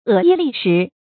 恶衣粝食 è yī lì shí 成语解释 粝：糙米。
成语繁体 惡衣糲食 成语简拼 eyls 成语注音 ㄜˋ ㄧ ㄌㄧˋ ㄕㄧˊ 常用程度 常用成语 感情色彩 贬义成语 成语用法 作宾语、补语；指粗劣的衣服和食物 成语结构 联合式成语 产生年代 古代成语 近 义 词 恶衣粗食 、 恶衣恶食 、 恶衣菲食 反 义 词 锦衣玉食 成语例子 《元史·张懋传》：“懋 恶衣粝食 ，率之以俭。”